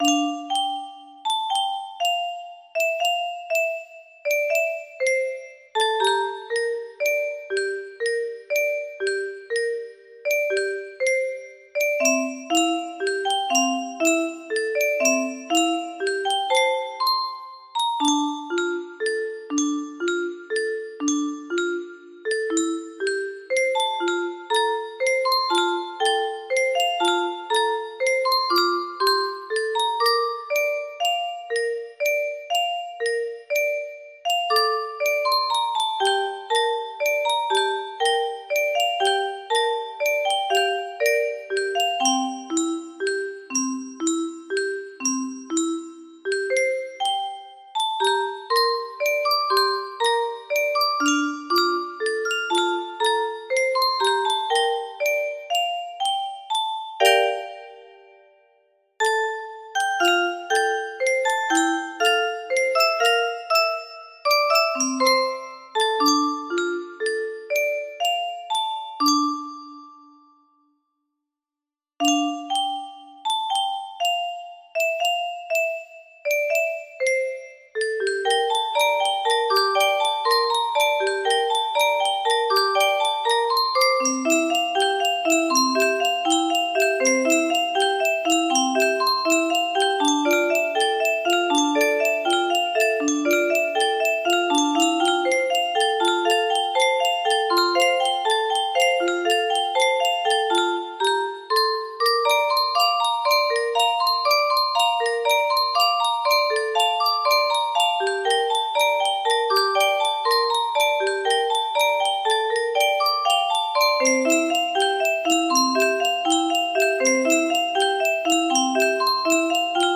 Grand Illusions 30 (F scale)
BPM 65
Music box & melodica version. Music box part.